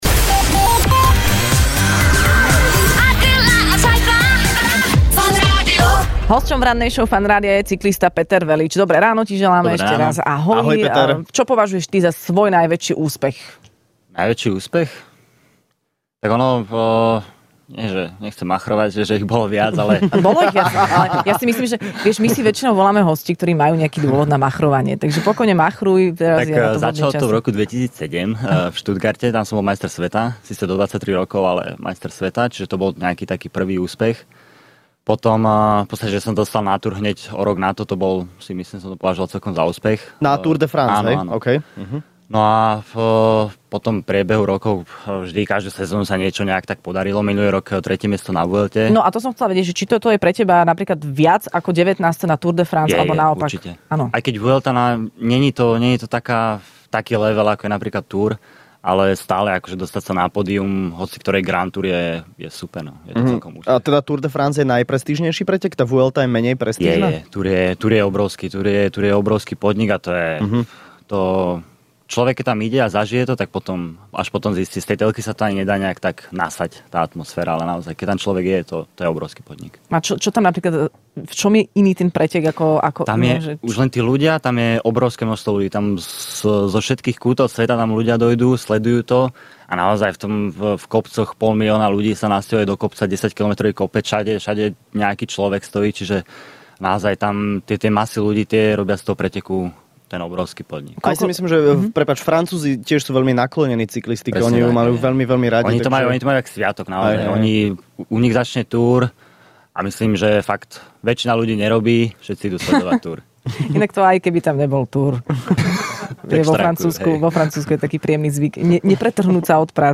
Hosťom v Rannej šou bol cyklista Peter Velits, ktorý sa vrátil z legendárnej Tour de France, kde sa umiestnil na celkovo vynikajúcom 19. mieste.